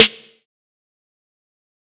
Sn (UpAllNight).wav